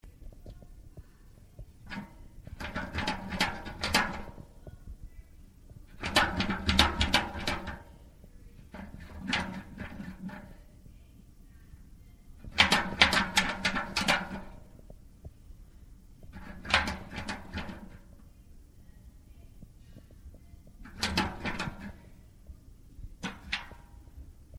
金属桶
描述：在外面录制，用手柄敲打金属桶。
标签： 场记录 铲斗 金属 拨浪鼓
声道立体声